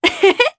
Naila-Vox-Laugh_jp.wav